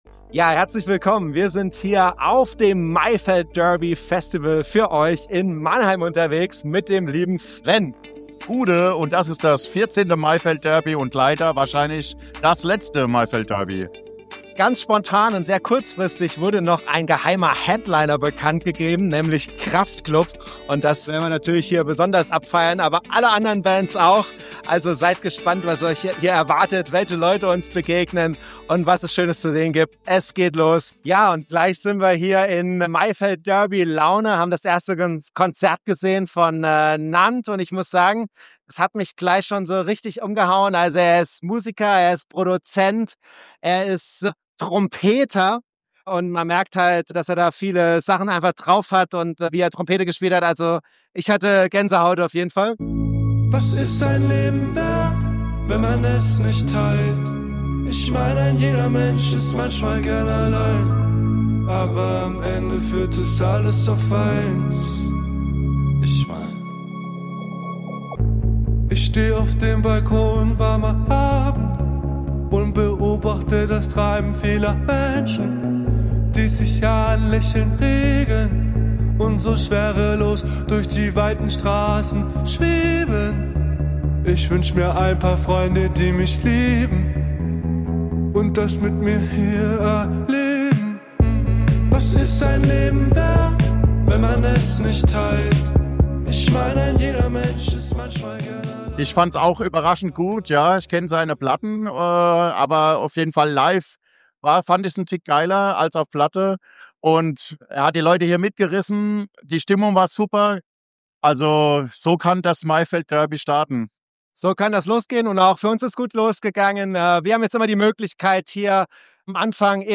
Sa., 18.04.2026, ab 20.15 Uhr Maifeld Derby 2025 – Festivalbericht vom Maimarktgelände Mannheim.